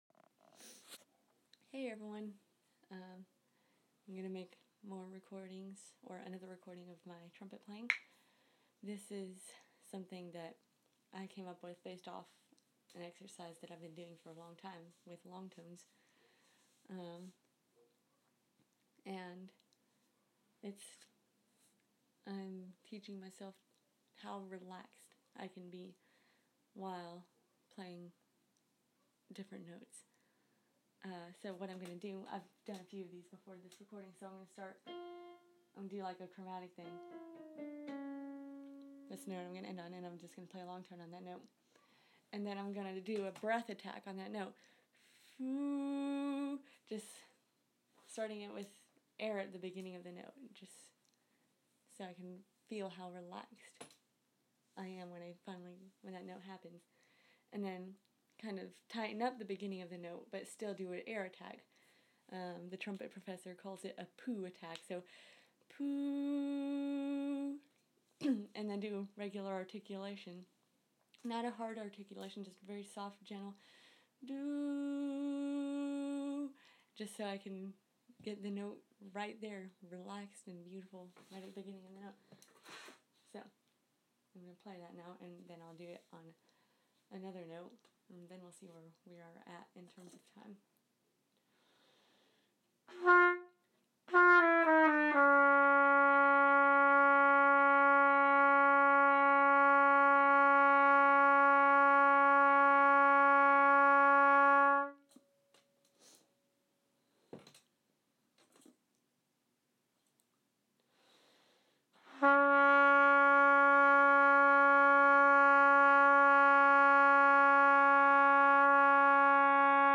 trumpet relaxation, long tone, and articulation exercise